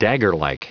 Prononciation du mot daggerlike en anglais (fichier audio)
Prononciation du mot : daggerlike